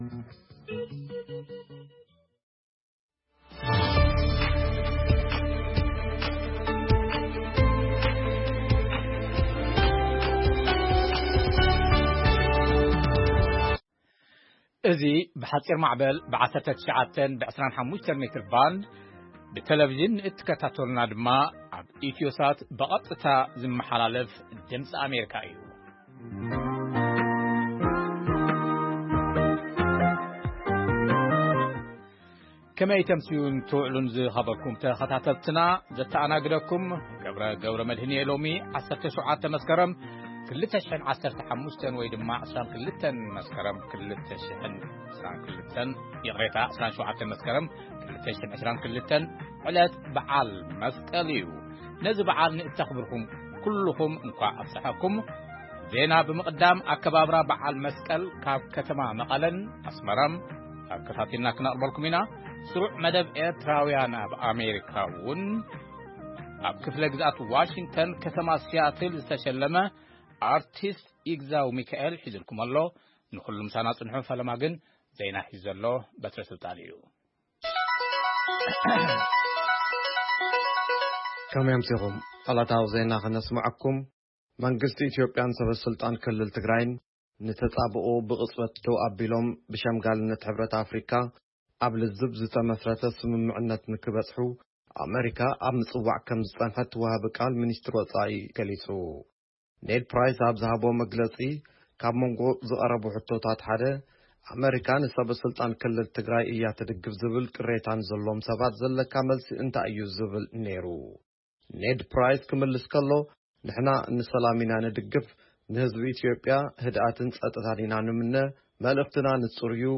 ፈነወ ድምጺ ኣመሪካ ቋንቋ ትግርኛ መስከረም 27,2022 ዜና ( መግለጺ ወሃቢ ቃል ሚንስትሪ ጉዳያት ወጻኢ ኣመሪካ ኣብ ጉዳይ ኢትዮጵያ: ኣብ ኡጋንዳ ዝተራእየ ሕማም ኢቦላን ካልኦትን) ጸብጻብ ስነ ስርዓት ኣከባብራ በዓል መስቀል ኣብ ከተማ መቐለን ኣስመራን መደብ ኤርትራዊያን ኣብ ኣመሪካ የጠቓልል